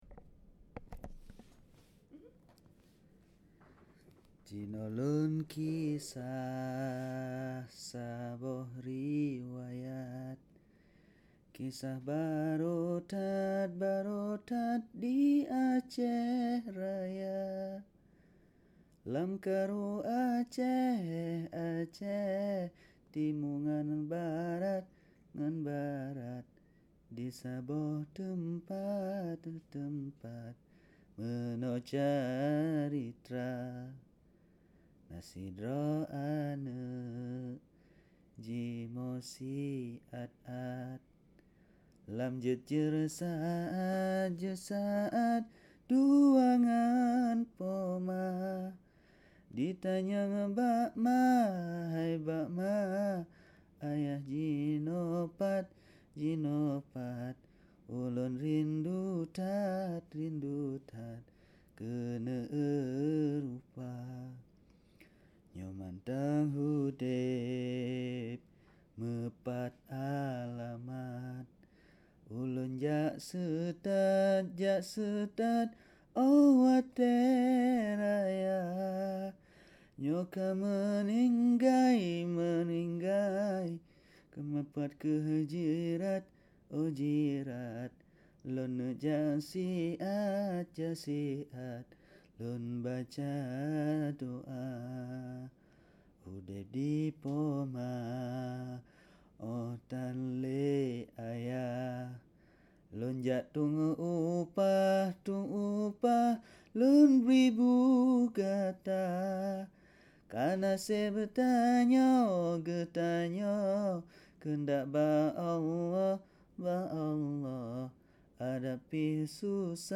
Achenese - Story/Song